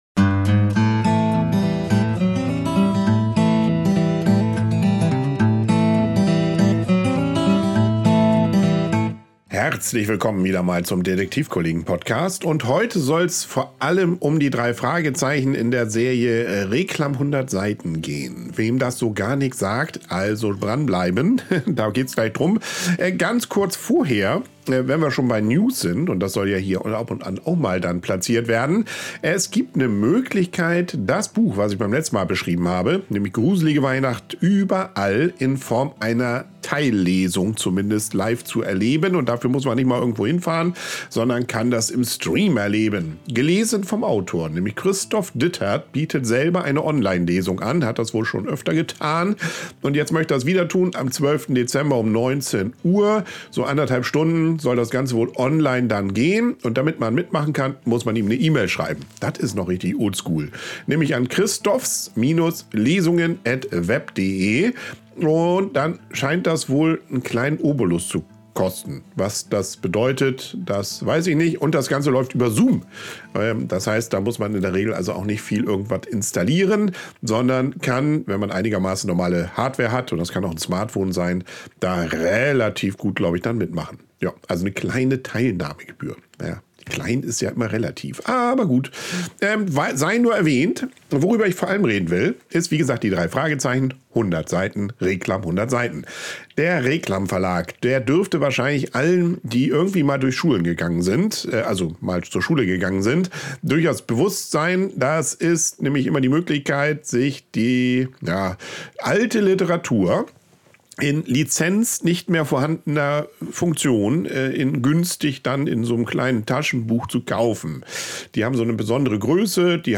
liest live aus seinem aktuellen Drei-Fragezeichen-Buch Gruselige Weihnacht überall.